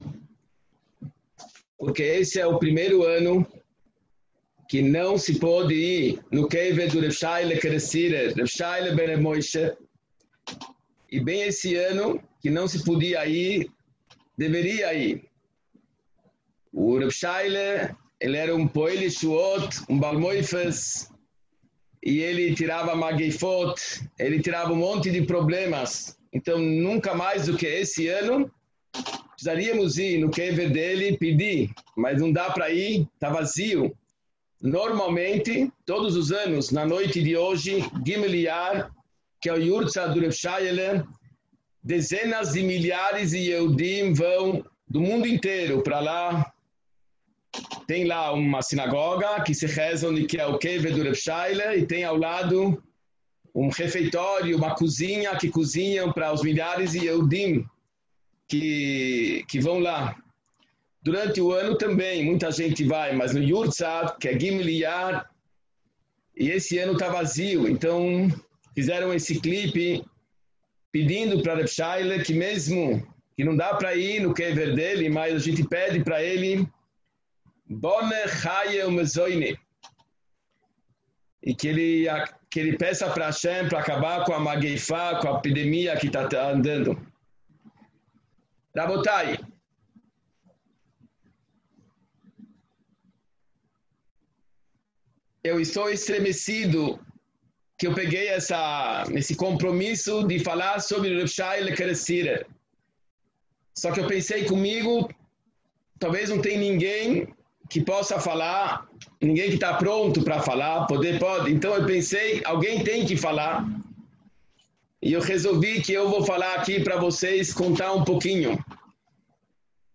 Shiur sobre Reb Yoshaiale Kerestirer Z’TL seus ensinamentos, trajetória de vida e legado.